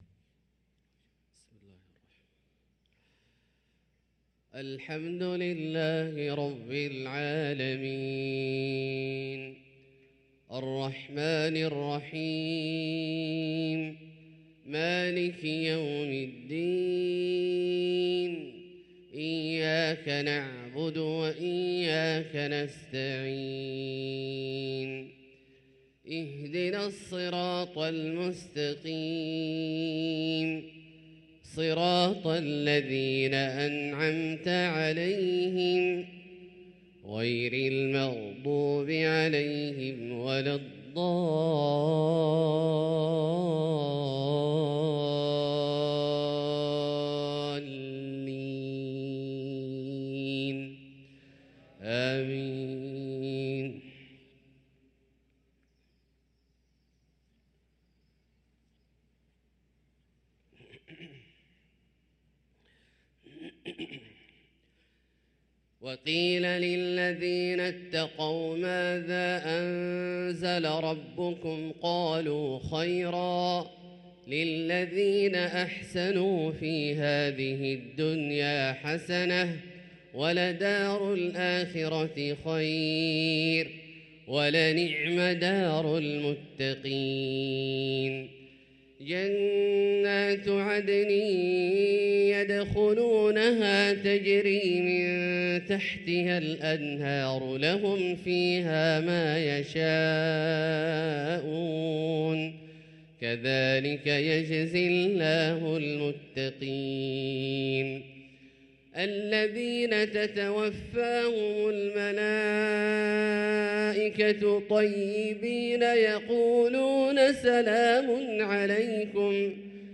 صلاة الفجر للقارئ عبدالله الجهني 28 صفر 1445 هـ
تِلَاوَات الْحَرَمَيْن .